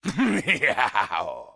beyond/Assets/Sounds/Enemys/Male/laugch4.ogg at 693dc35a9fdbffa4b8a9119b4e03e4cc4b1d877b
laugch4.ogg